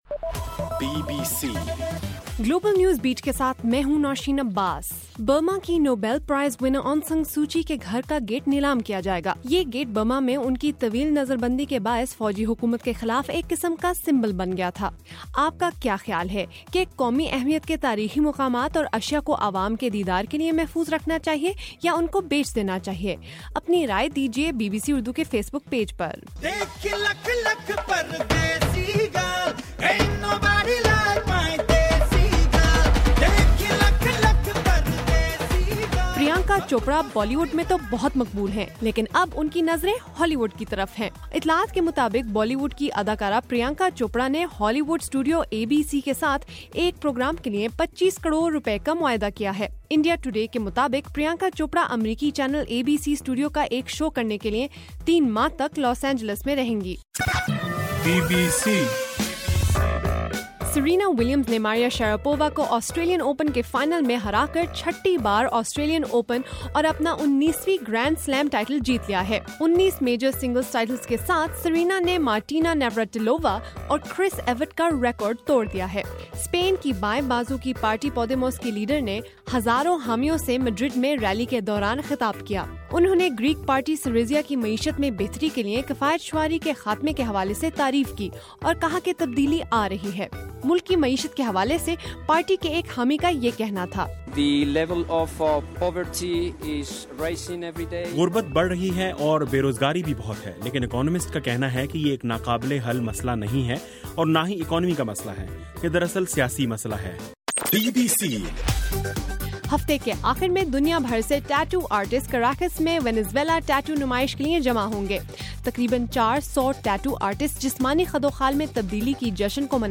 فروری 1: صبح 1 بجے کا گلوبل نیوز بیٹ بُلیٹن